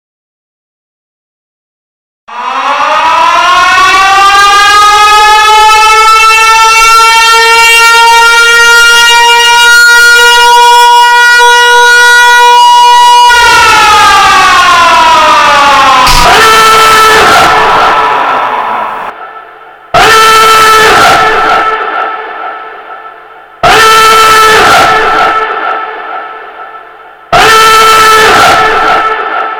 Ну и сам звук этого сигнала тут
В принципе, тут можно чисто интуитивно догадаться (по характеру звука), что какой-то пиздец наступил, если из всех динамиков автоматизированной системы централизованного оповещения орёт такой сигнал.